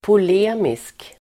Ladda ner uttalet
Uttal: [pol'e:misk]